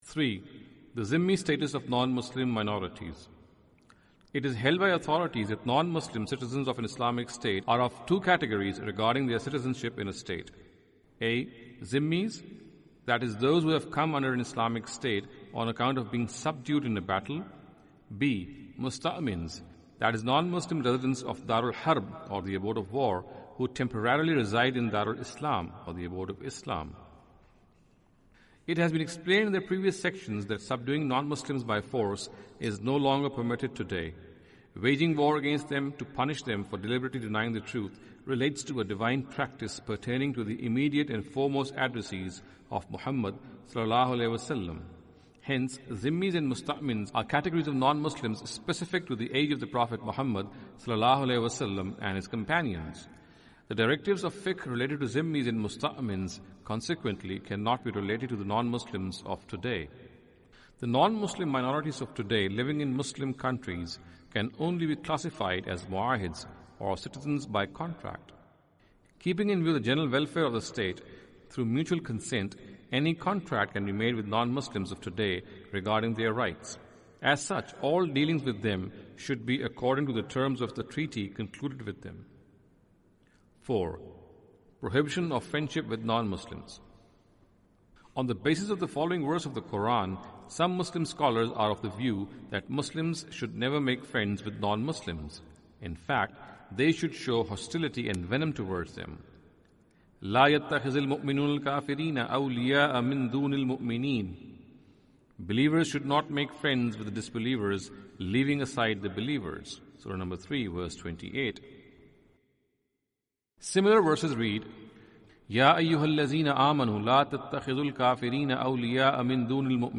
Category: Audio Books / Playing God /